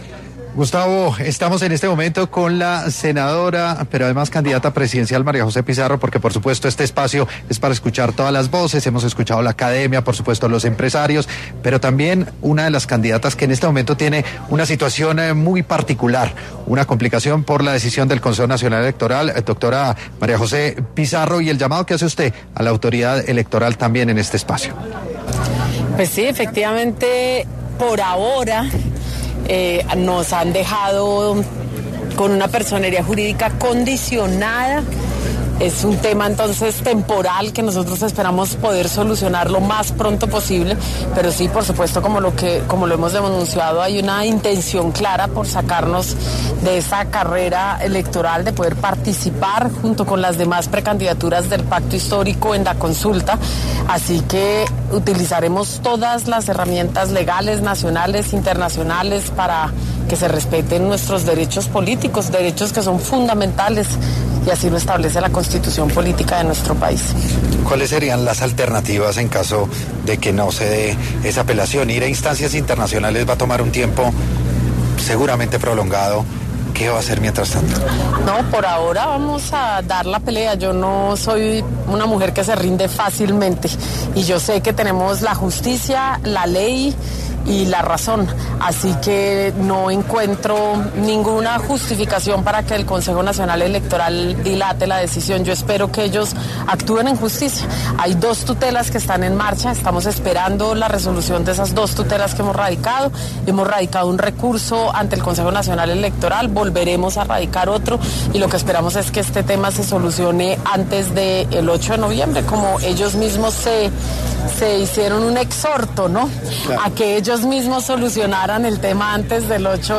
Desde el Festival de las Ideas de PRISA Media, que se desarrolla en Villa de Leyva, Pizarro denunció una clara intención de sacarlos de la carrera electoral y de impedir su participación en la consulta de octubre del Pacto Histórico.